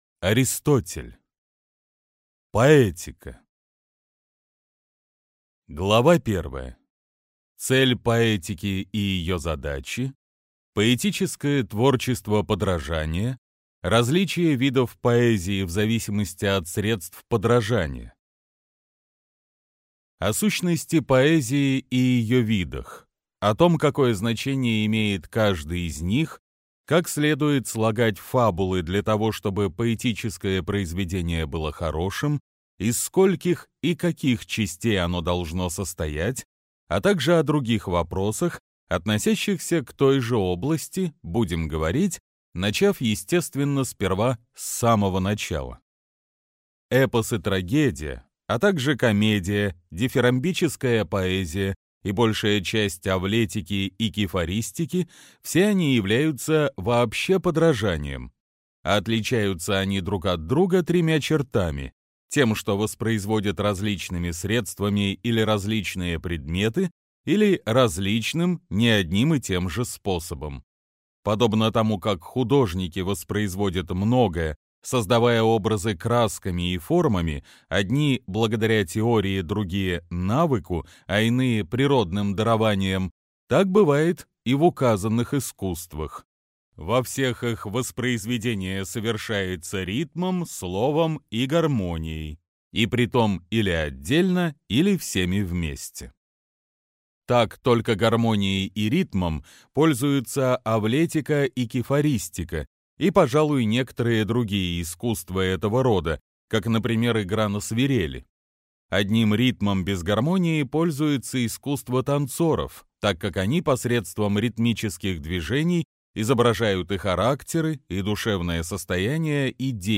Аудиокнига Поэтика | Библиотека аудиокниг